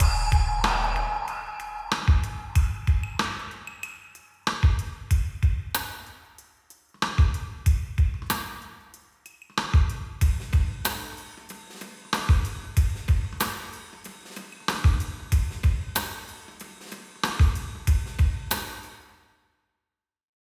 SILK-Tutorial-DRUM-TRACK.wav